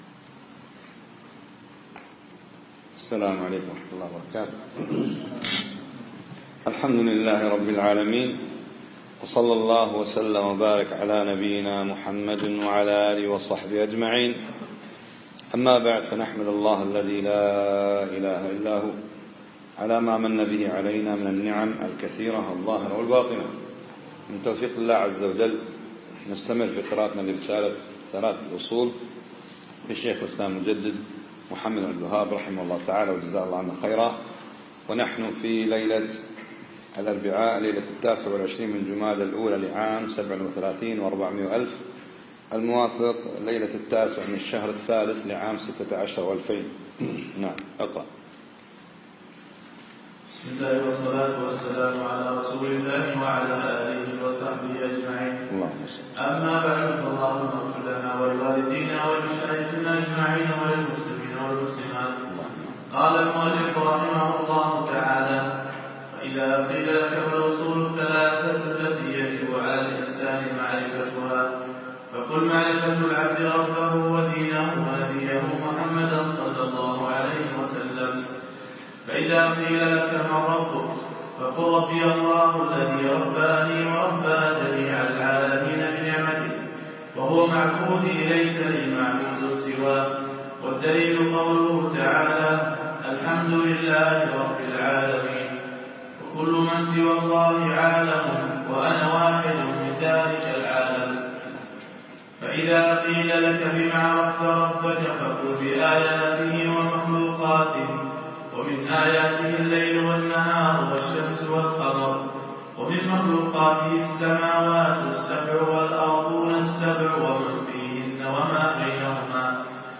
مغرب الثلاثاء 28 جمادى الأولى 1437 الموافق 8 3 2016 مسجد سعد السلطان الفنطاس